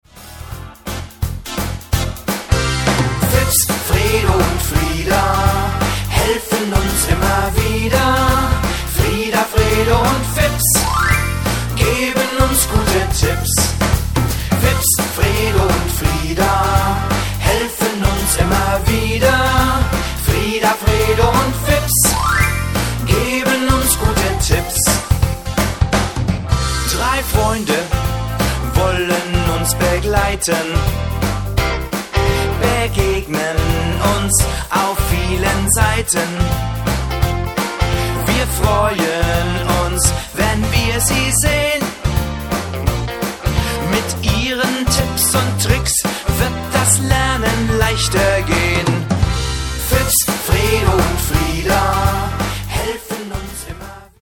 (vocal und playback)